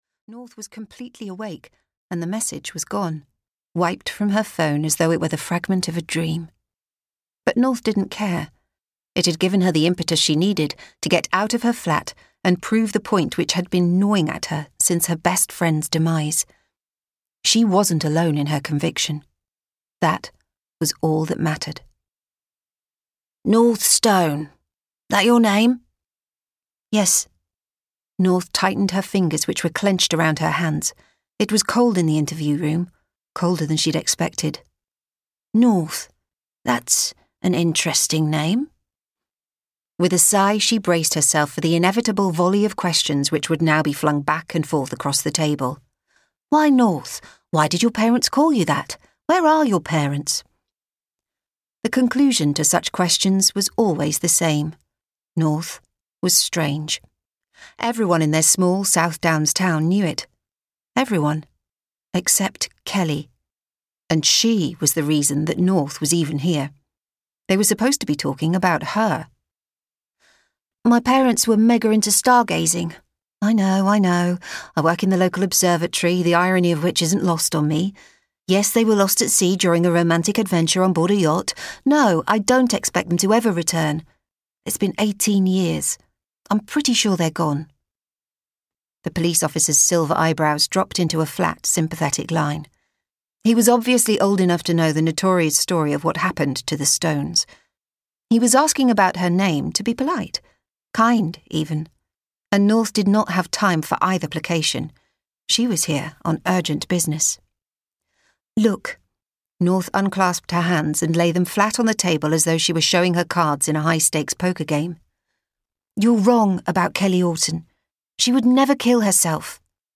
Dead Girls Can't Lie (EN) audiokniha
Ukázka z knihy